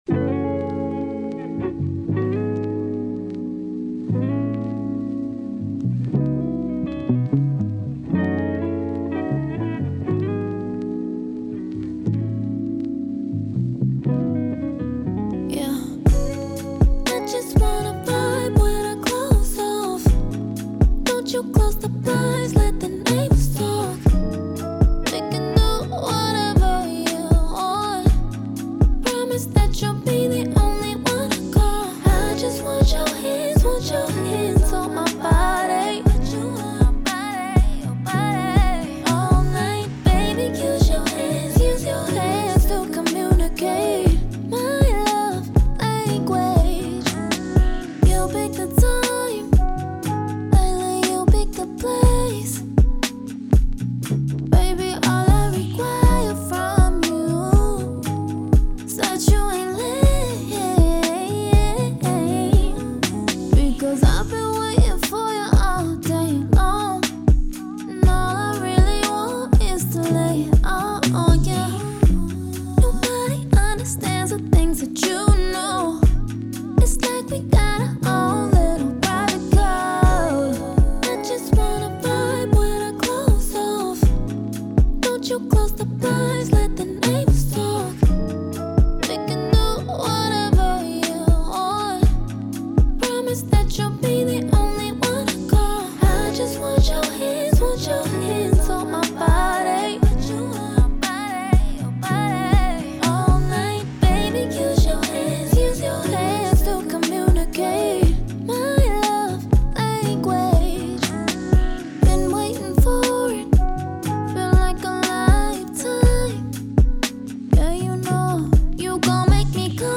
R&B
B Major